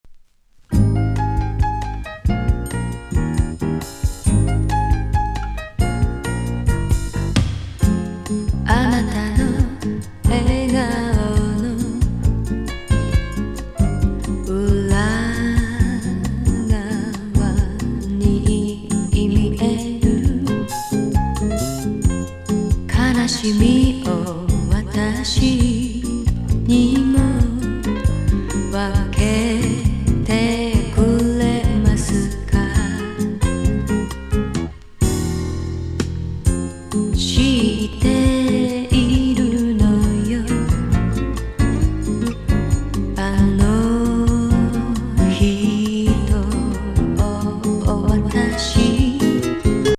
アダルト・ジャジー・メロウ・ボサ